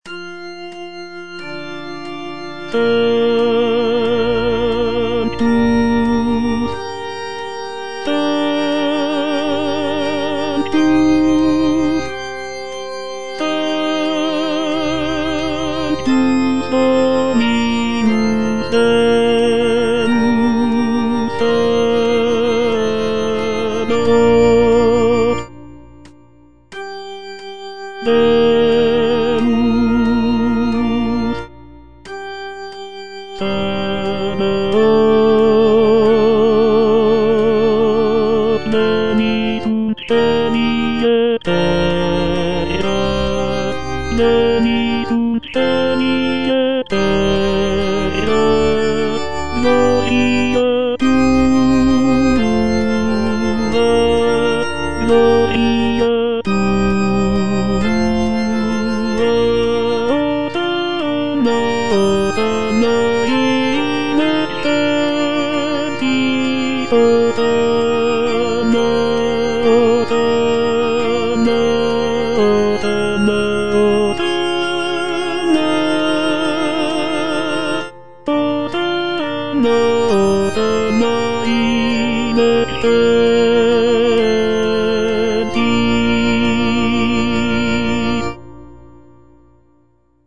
Sanctus - Tenor (Voice with metronome) Ads stop